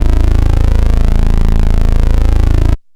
ANALOG 3 1.wav